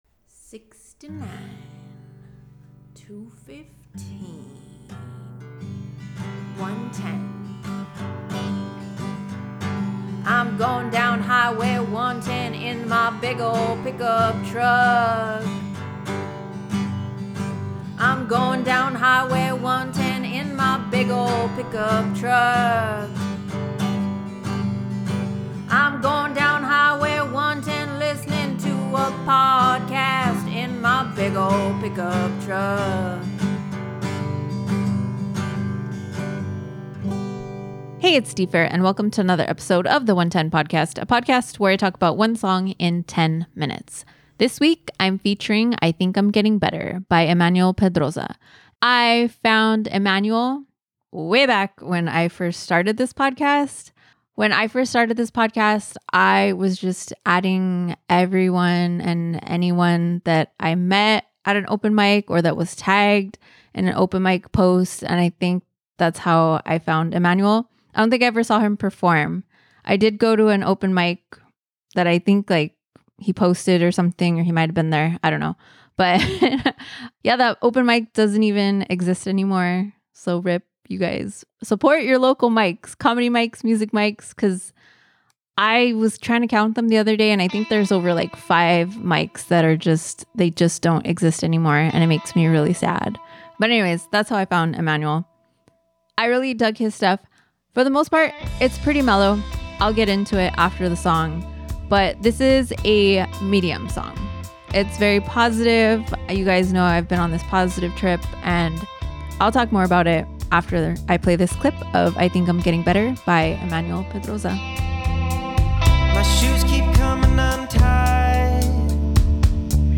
I’m trying to keep the positive vibes going